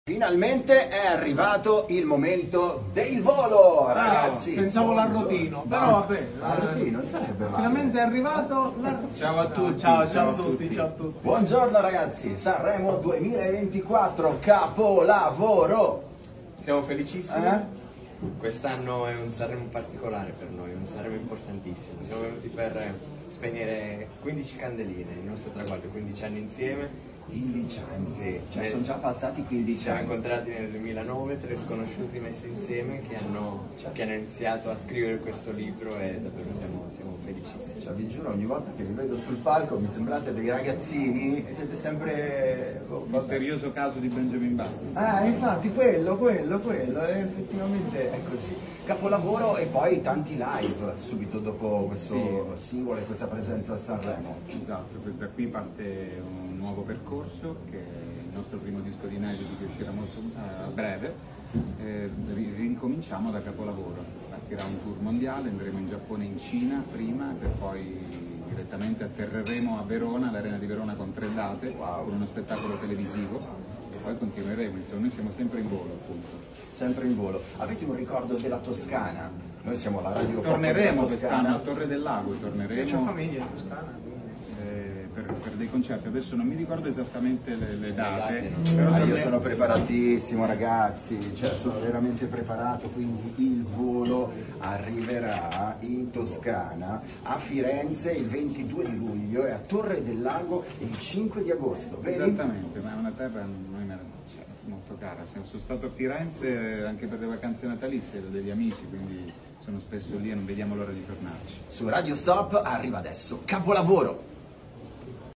Festival di Sanremo con Radio Stop!
Radio Stop – Intervista a I VOLO
Intervista-a-I-VOLO.mp3